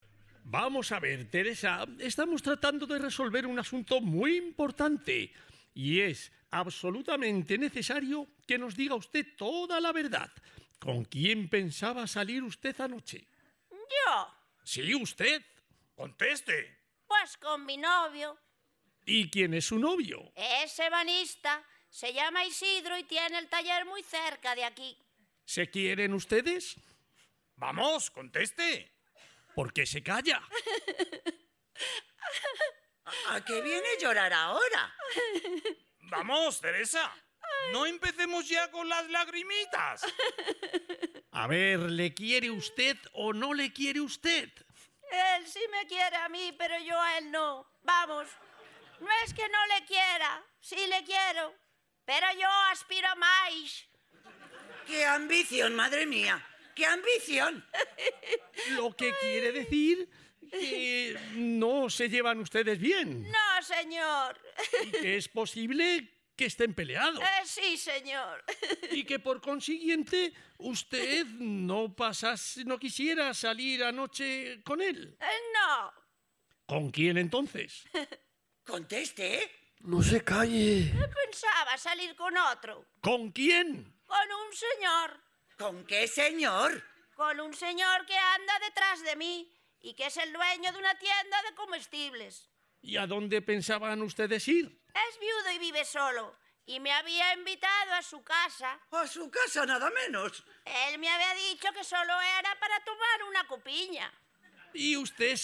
“Milagro en casa de los López”, de Miguel Mihura. El jurado ha valorado “la buena y bien compensada ecualización de la grabación, así como el buen ritmo de lectura, así como la acertada colocación de las réplicas”.